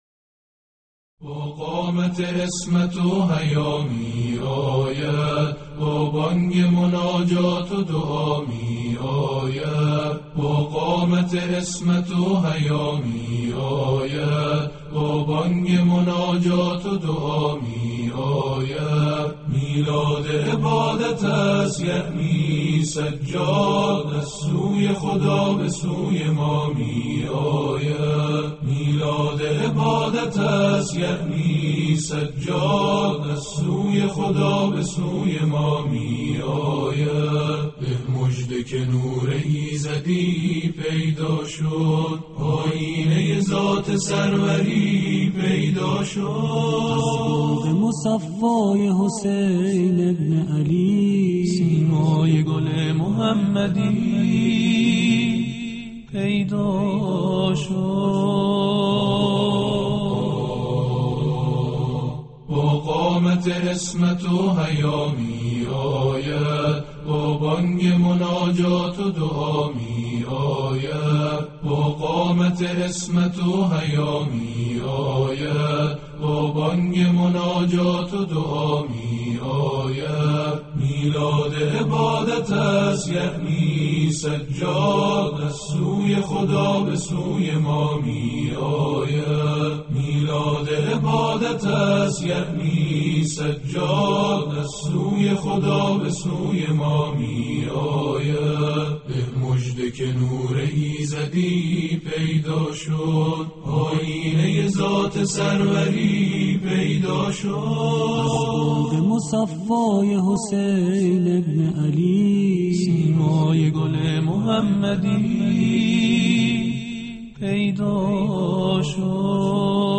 حرم مطهر رضوی و پخش زنده
همخوانی بمناسبت ولادت امام سجاد علیه السلام